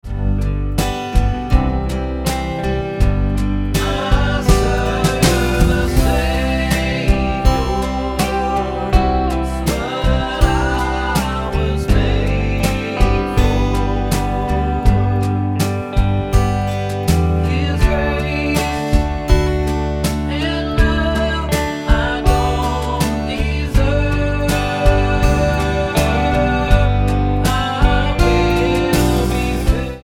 Tonart:G mit Chor